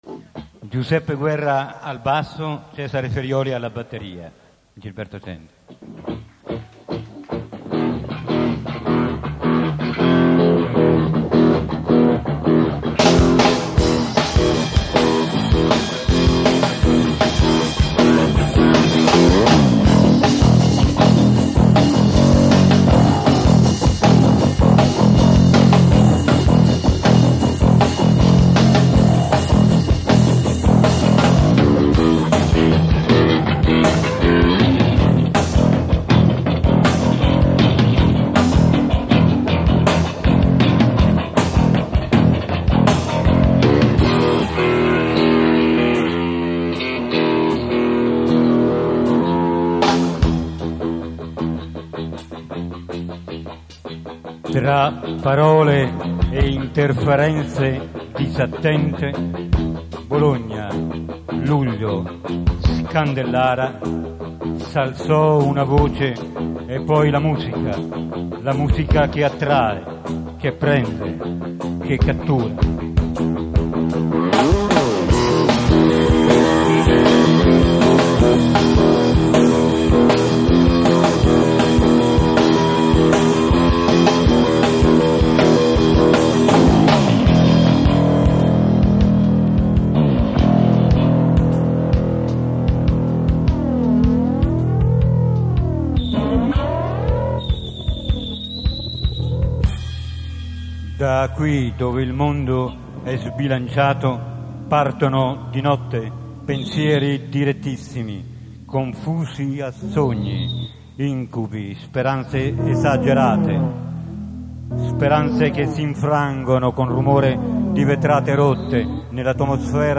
AUDIO LIVE
The X-Raymen
Scandellara Rock Festival